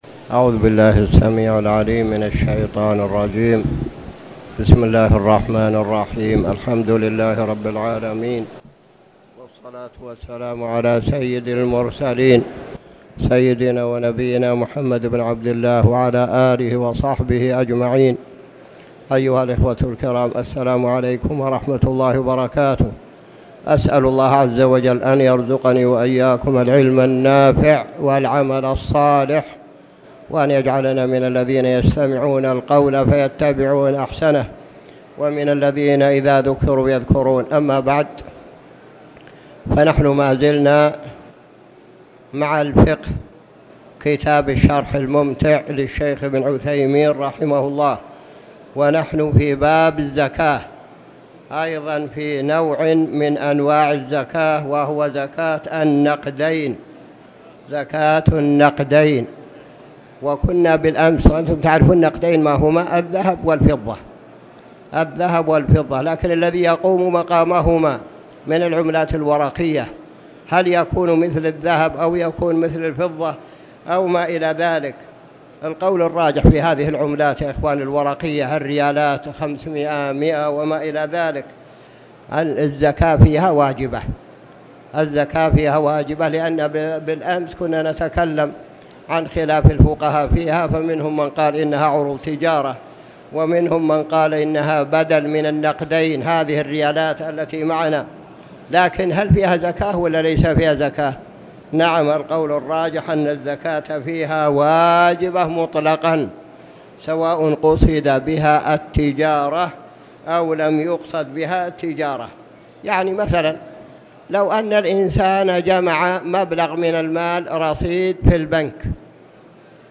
تاريخ النشر ١٦ جمادى الآخرة ١٤٣٨ هـ المكان: المسجد الحرام الشيخ